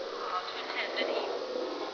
Electronic Voice Phenomena
All of these are way below 300Hz